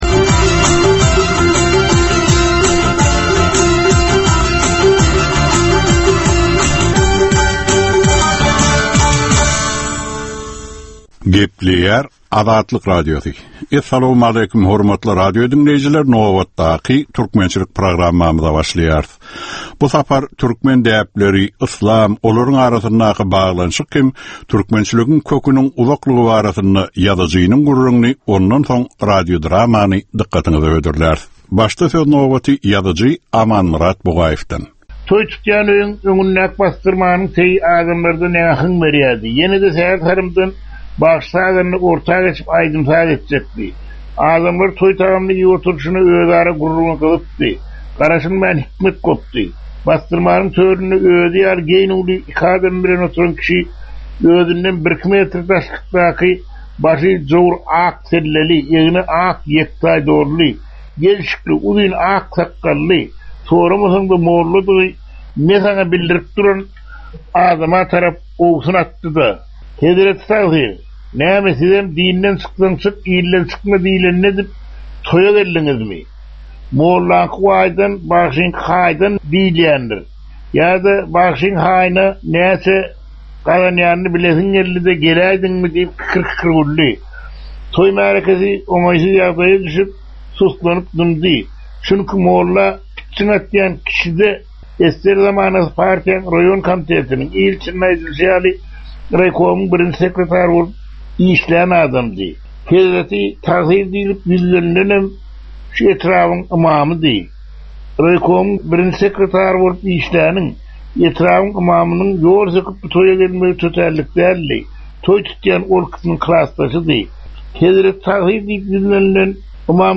Türkmen halkynyň däp-dessurlary we olaryň dürli meseleleri barada 10 minutlyk ýörite gepleşik. Bu programmanyň dowamynda türkmen jemgyýetiniň şu günki meseleleri barada taýýarlanylan radio-dramalar hem efire berilýär.